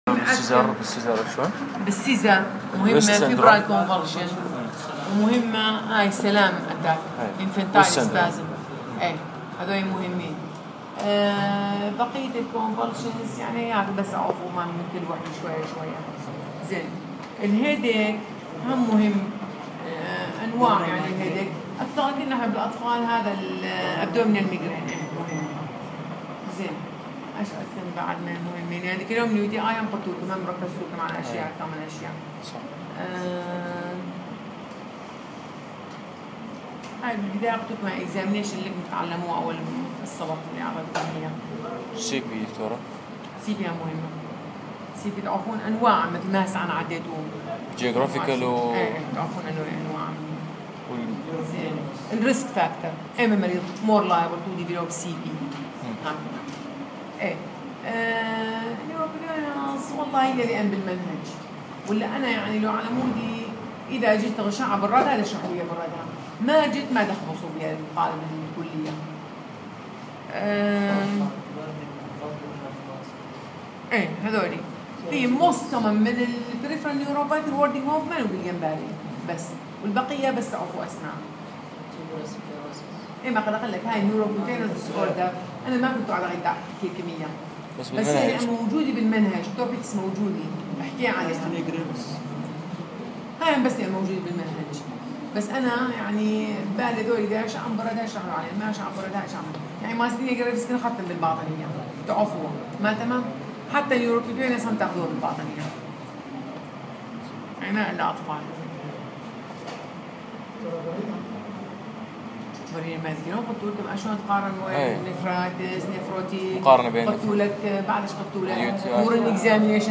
الأطفال > محاضرة رقم 3 بتاريخ 2015-10-26